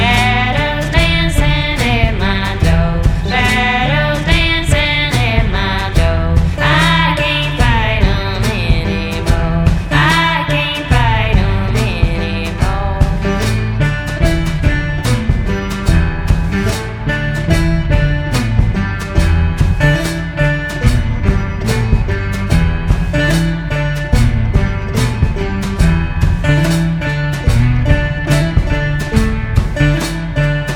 Rock et variétés internationales